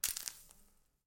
带闪光灯的相机
描述：声片相机闪光灯充电